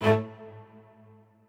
admin-leaf-alice-in-misanthrope/strings34_5_001.ogg at main